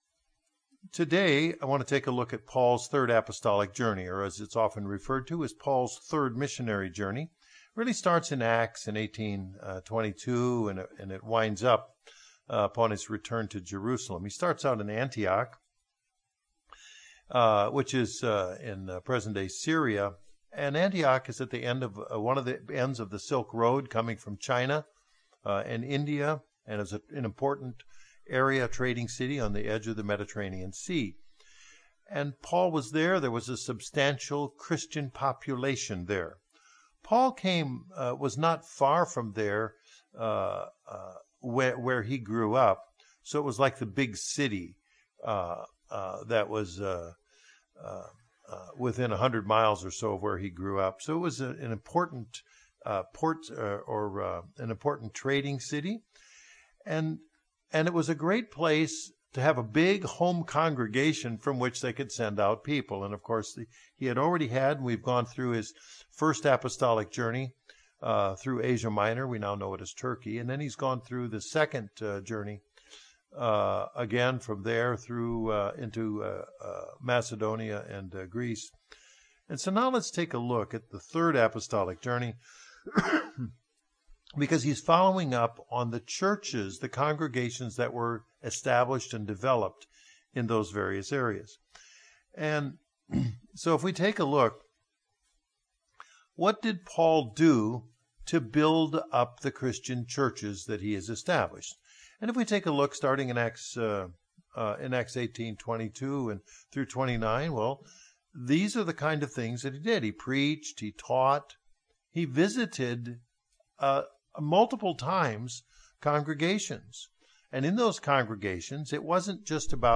We continue our Bible Study into the Apostle Paul's final missionary journey.
Given in Northwest Arkansas